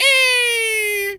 bird_large_squawk_04.wav